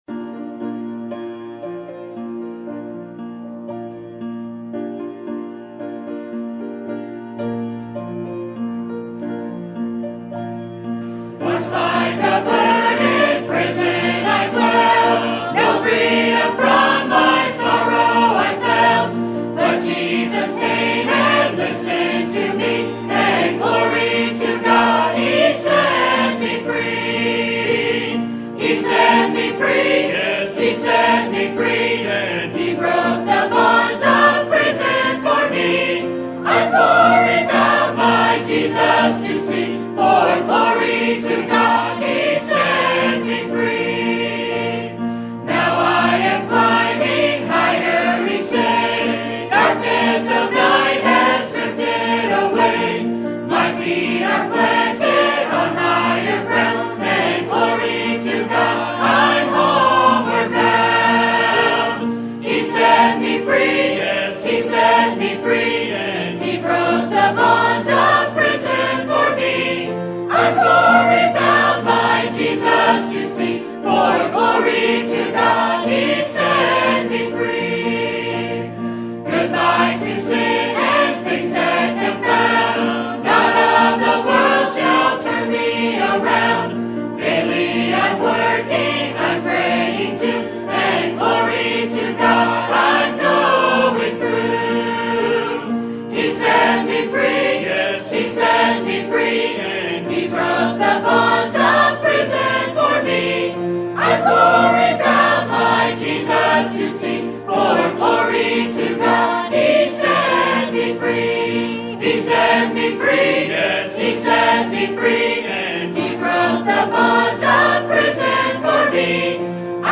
Sermon Date
Sermon Topic: Winter Revival Sermon Type: Special Sermon Audio: Sermon download: Download (26.93 MB) Sermon Tags: Matthew Prayer Presence Father